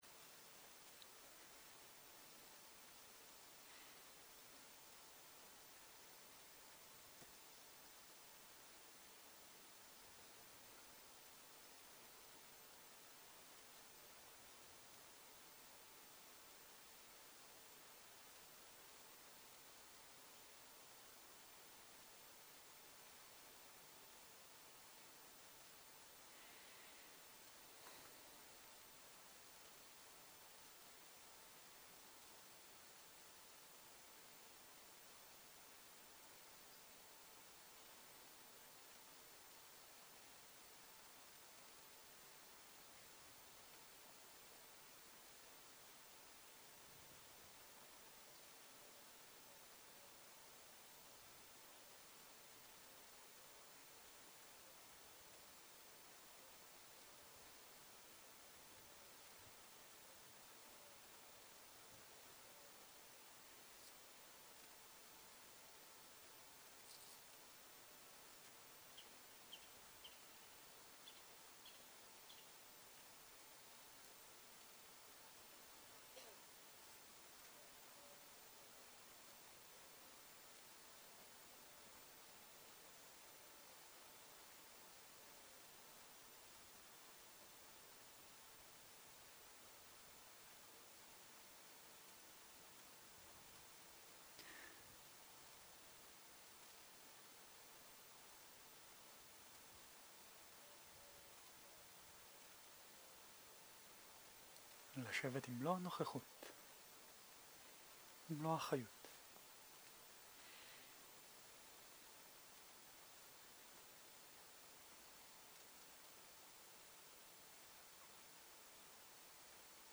מדיטציה מונחית צהריים
סוג ההקלטה: מדיטציה מונחית